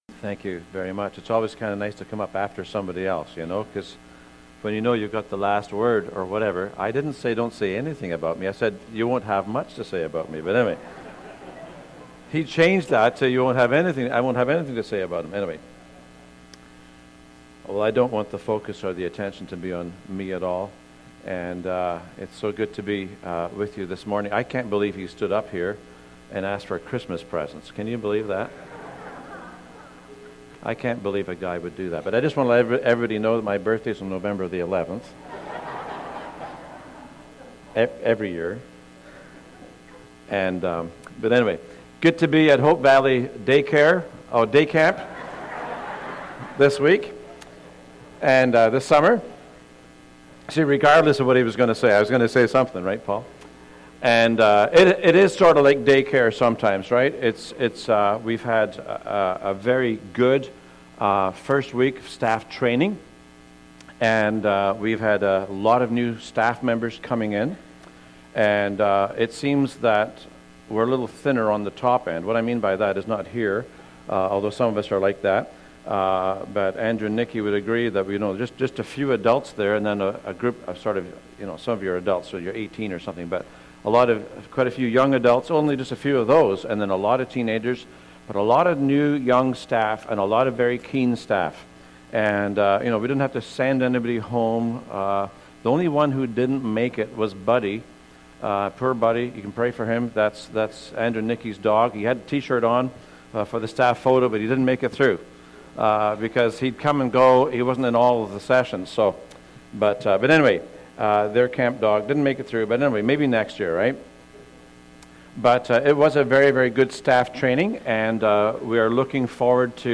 Hebrews 8 Service Type: Family Bible Hour No evening service during the month of July « How Not To Be An Edomite God’s Very Best